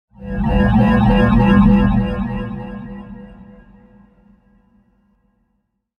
Eerie Cosmic Sci-Fi Transition Sound Effect
Description: Eerie cosmic sci-fi transition sound effect. A mysterious and futuristic sound ideal for scenes with satellites flying and passing in orbit. It creates a sense of suspense and cosmic wonder with smooth atmospheric tones.
Eerie-cosmic-sci-fi-transition-sound-effect.mp3